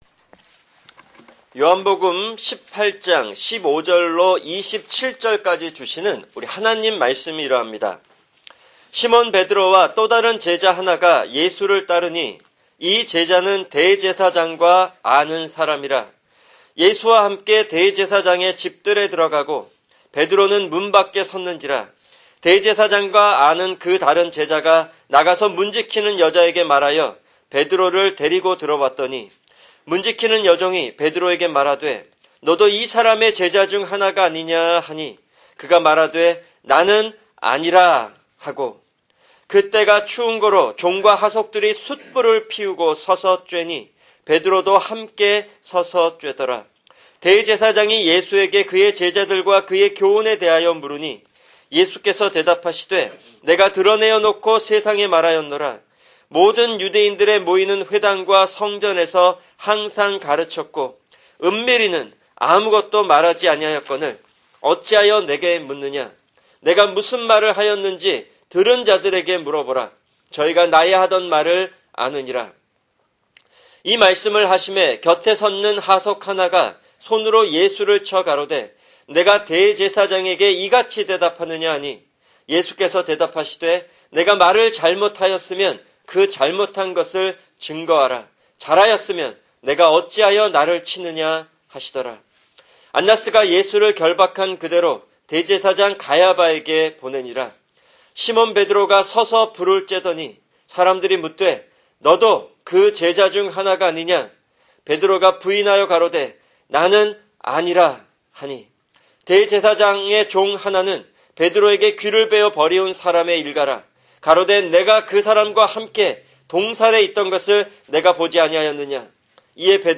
[주일 설교] 요한복음 18:15-27